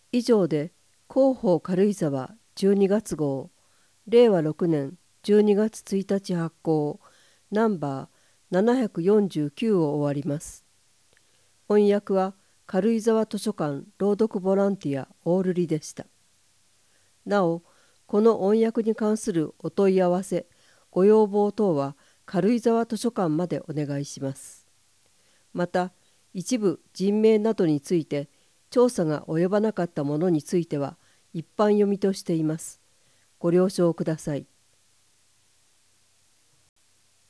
音声データ　軽井沢図書館朗読ボランティア「オオルリ」による朗読です